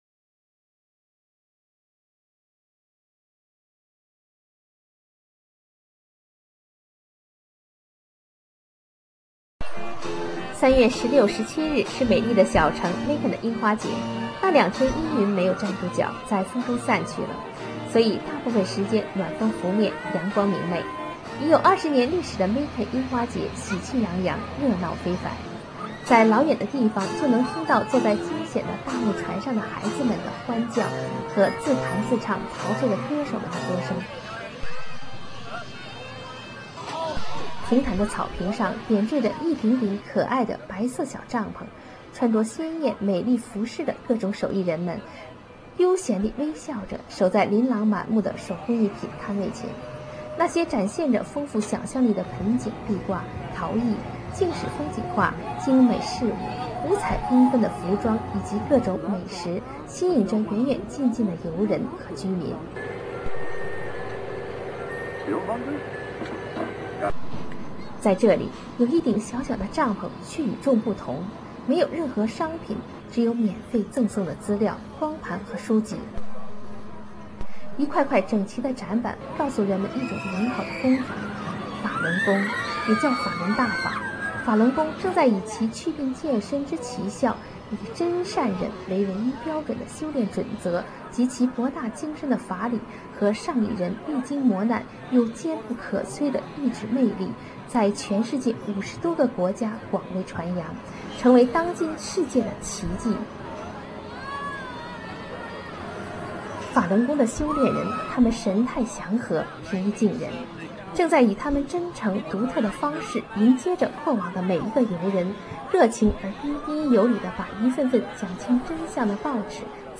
cherry_blosoom_festival_256k.ra